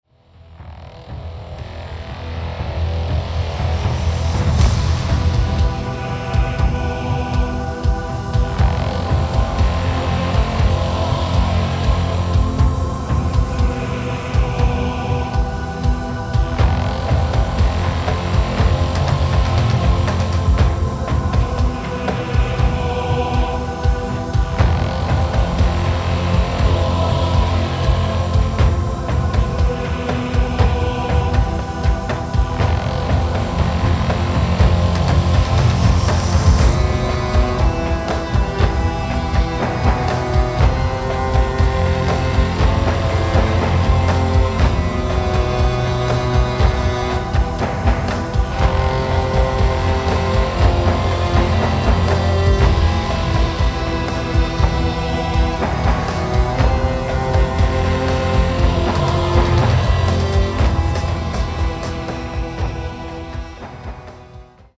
Epic Music!